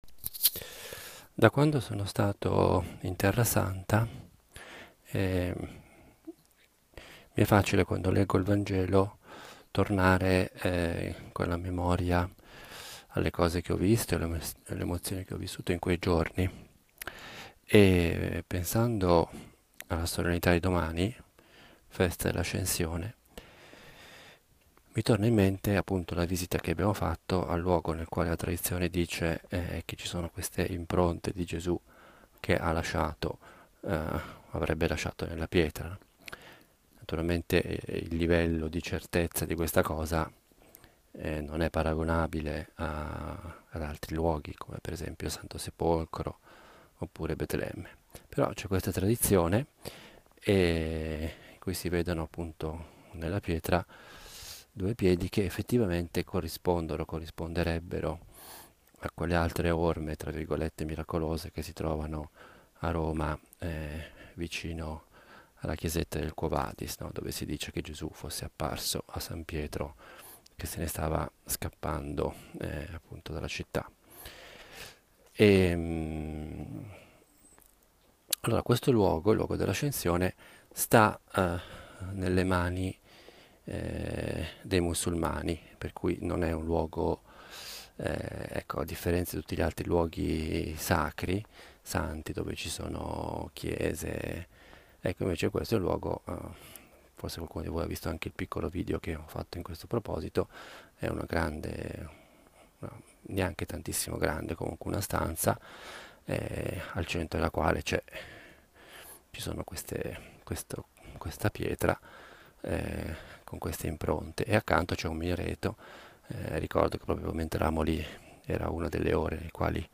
Pausa caffè a Nazareth è una riflessione breve, di otto minuti, sul vangelo della domenica. Una meditazione nella quale cerco di collegare il vangelo con la vita quotidiana e con la nostra prosa più normale: la frase di un giornale, le parole di una canzone. Vorrei avesse il carattere piano, proprio di una conversazione familiare. Io la intendo come il mio dialogo personale – fatto ad alta voce – con Dio e con la Madonna.